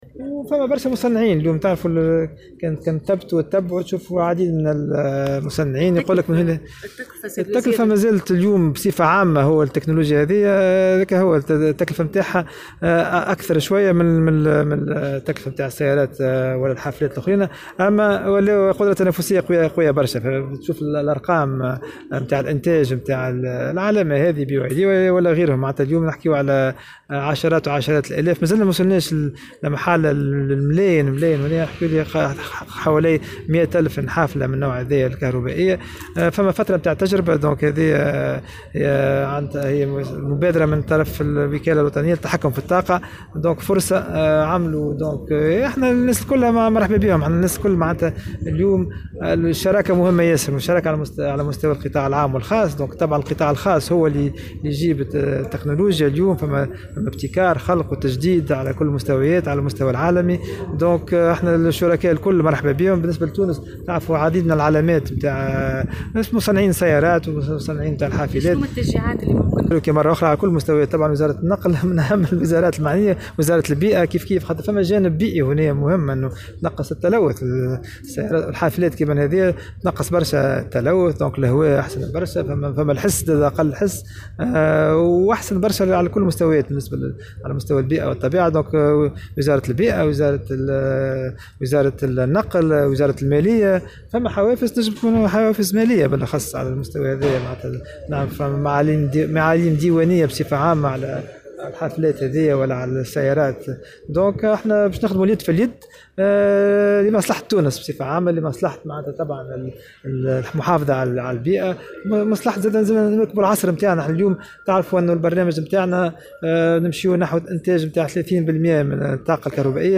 أكد وزير الصناعة والمؤسسات الصغرى والمتوسطة;سليم الفرياني في تصريح لمراسلة الجوهرة "اف ام" اليوم أن تونس ذاهبة نحو الإنتقال الطاقي نظرا لمزاياه العديدة على البيئة وفي اطار مواكبتها للتحول الذي يشهده العالم في هذا المجال.